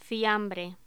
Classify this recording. Locución: Fiambre